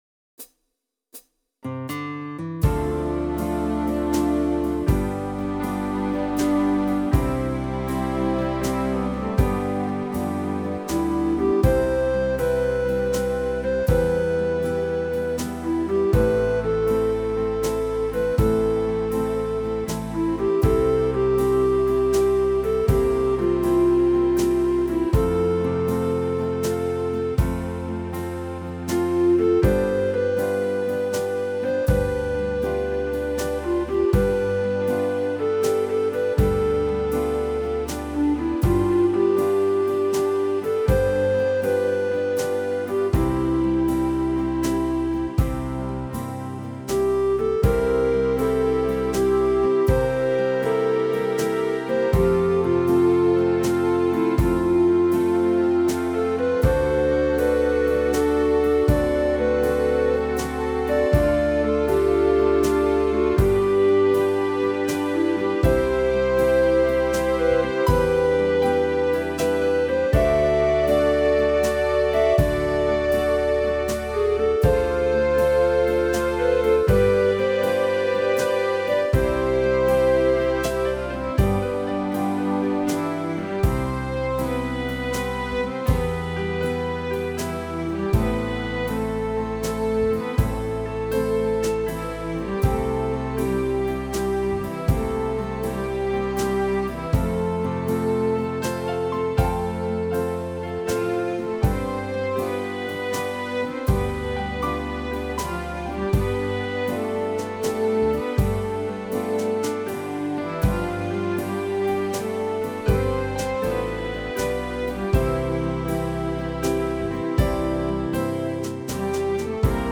(Pop/Rock List)